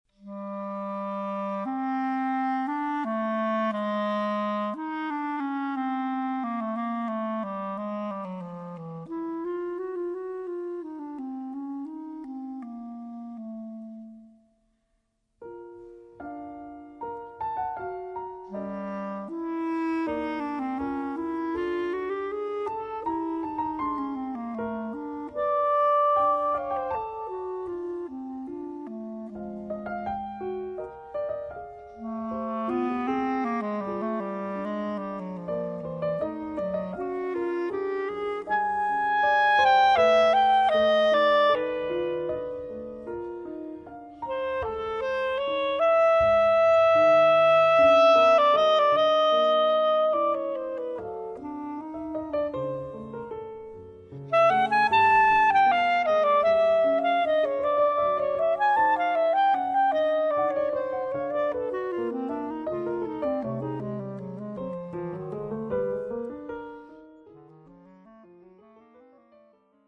研ぎ澄まされたセンスが極限に達し結晶と化した演奏
piano
sax